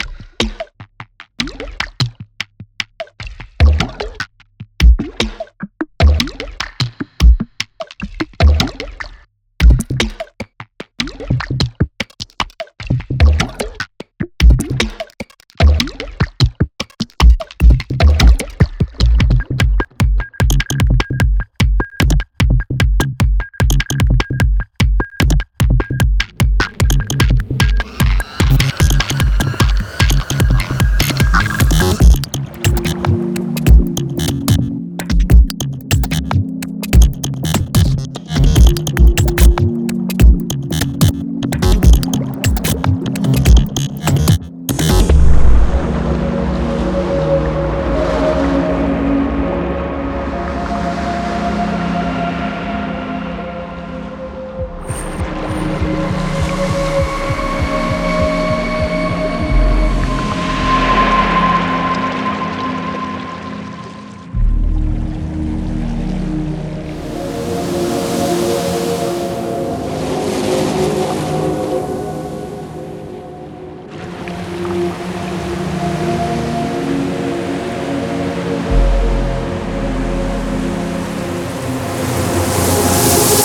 Leftfield Bass
All sounds were recorded using a Sound Devices 633 with Sennheiser MKH416, hand-made piezoelectric mics and a Sony PCM-D100.